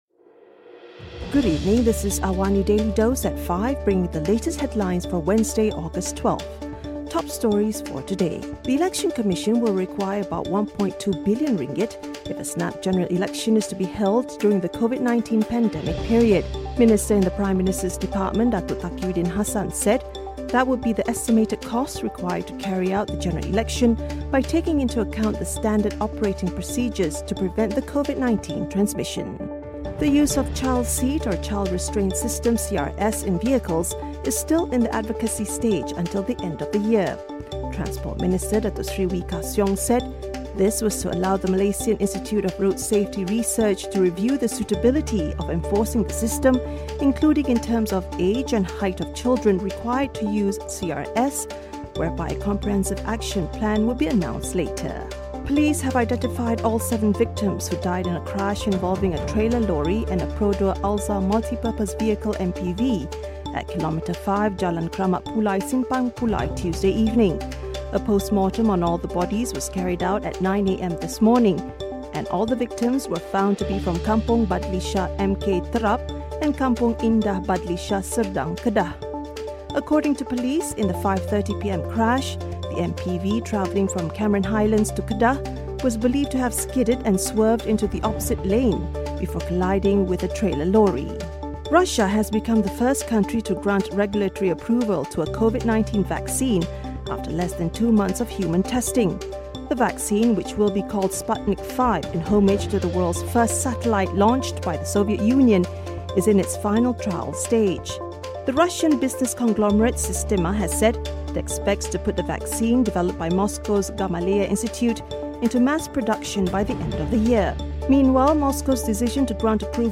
Listen to the top five stories of the day, reporting from Astro AWANI newsroom — all in 3 minutes.